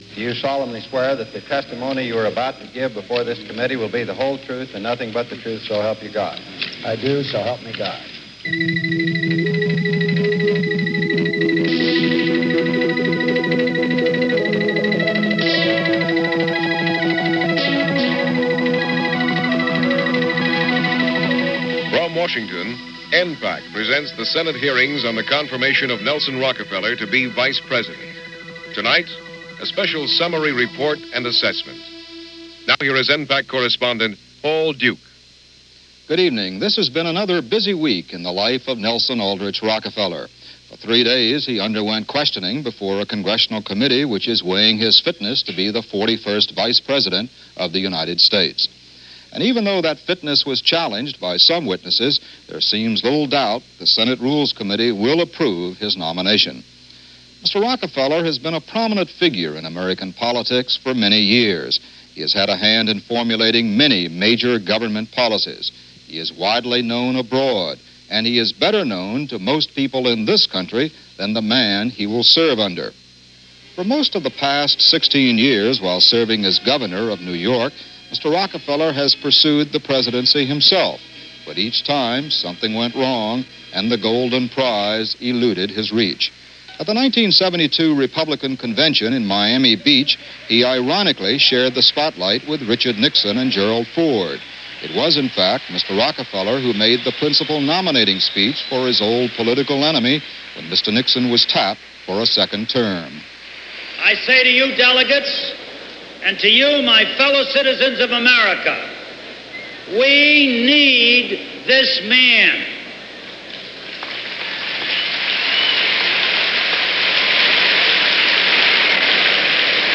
Nelson Rockefeller vice-President confirmation hearings - Re-cap - September 27, 1974 - re-cape of the week's testimony and commentary.